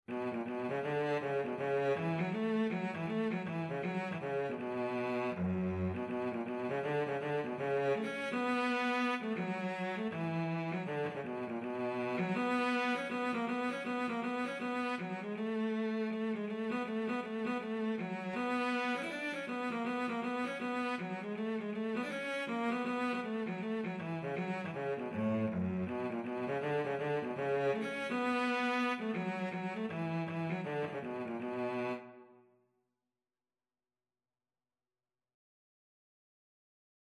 Free Sheet music for Cello
B minor (Sounding Pitch) (View more B minor Music for Cello )
4/4 (View more 4/4 Music)
Cello  (View more Easy Cello Music)
Traditional (View more Traditional Cello Music)